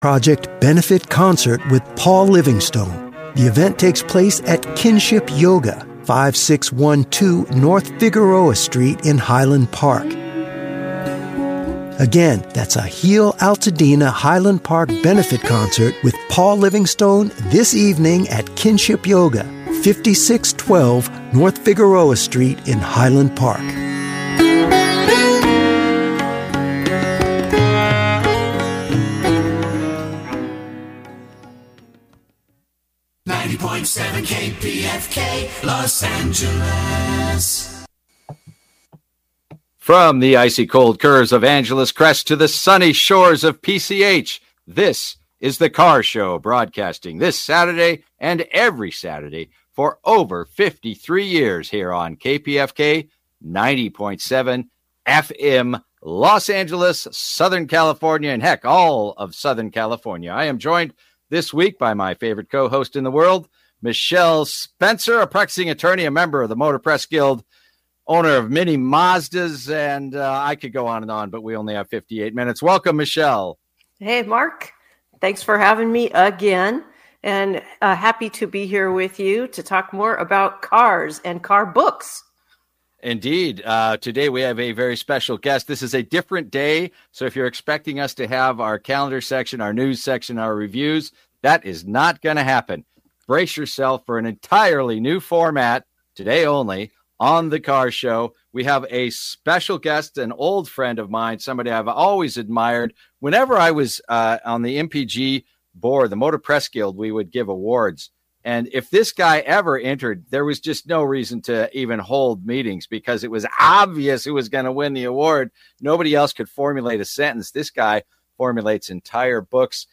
Everything about the automotive world including listener call-ins, event calender, industry news, racing news,new products, vehicle road tests,classic and collector vehicles, in-studio and call-in guests on all automotive and related subjects---and it has been on since 1973.